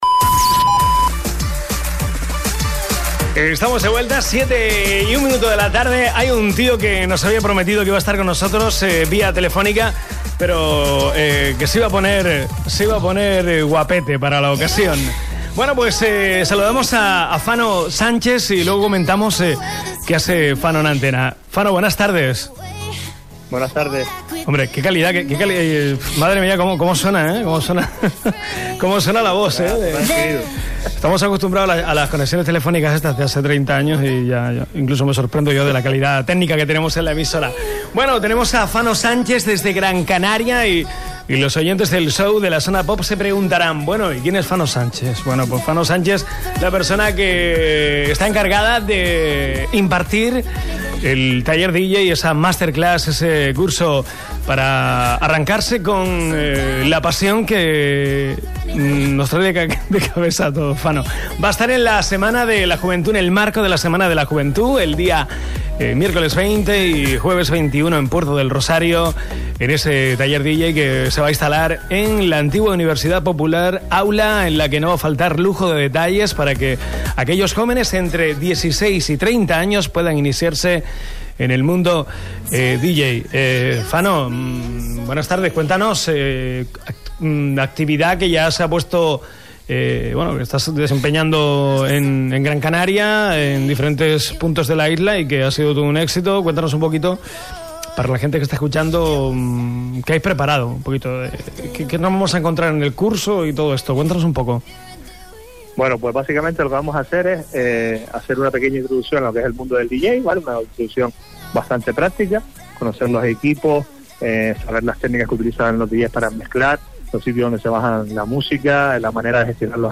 Entrevista Radio Sintonía – Taller Iniciación al DJ en Puerto del Rosario (Fuerteventura) Junio 2018